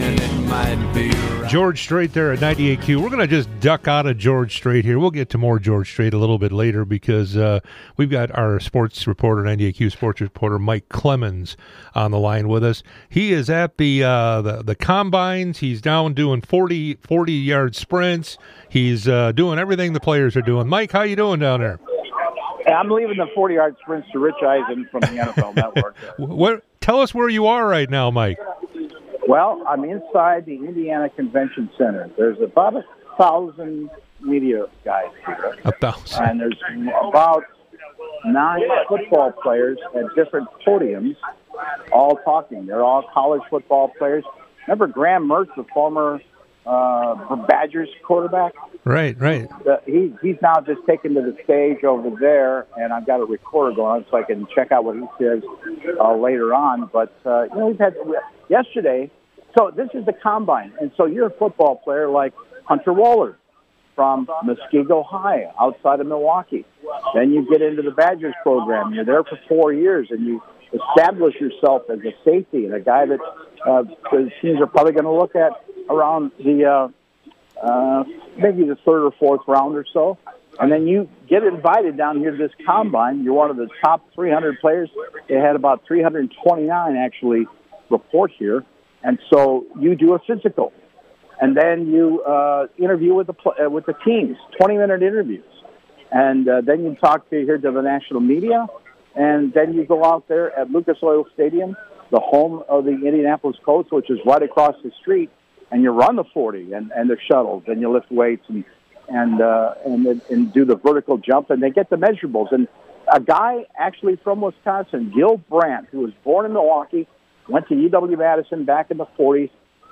98q interviews
LIVE from NFL Scouting Combine in Indianapolis